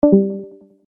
user-left.mp3